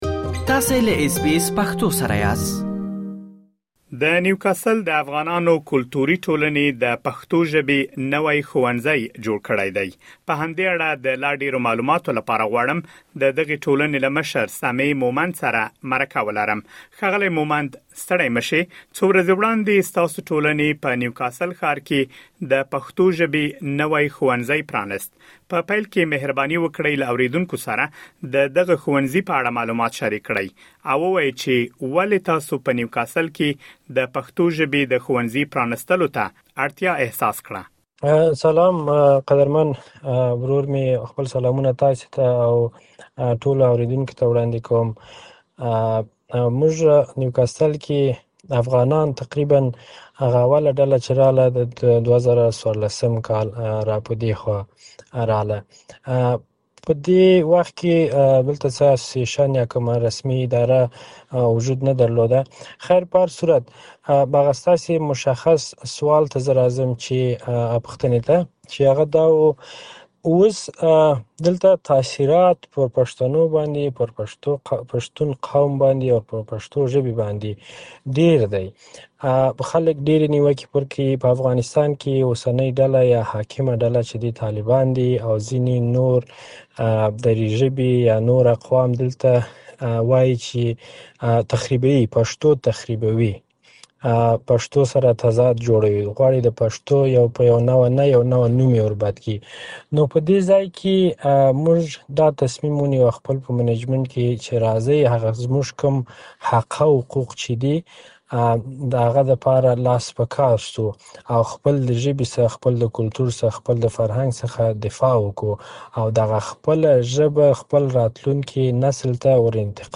مهرباني وکړئ مهم معلومات په مرکې کې واورئ.